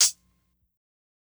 Closed Hats
HIHAT_INSANITY.wav